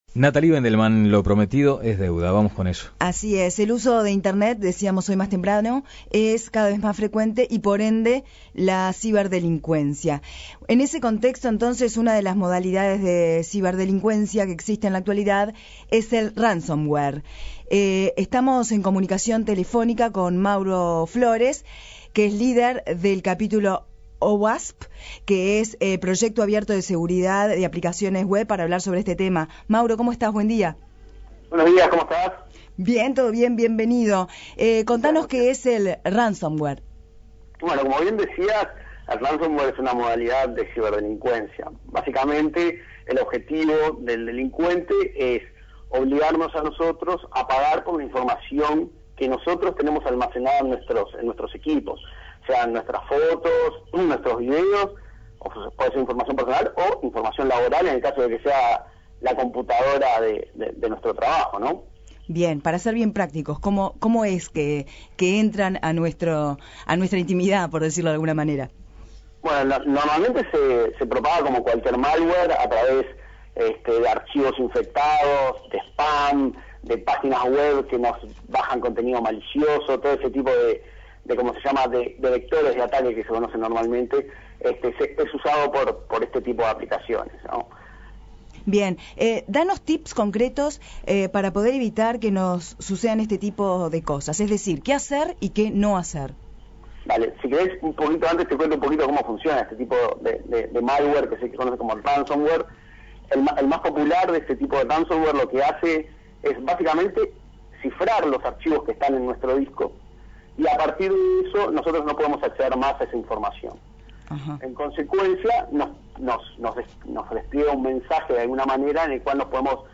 habló en La Mañana de El Espectador.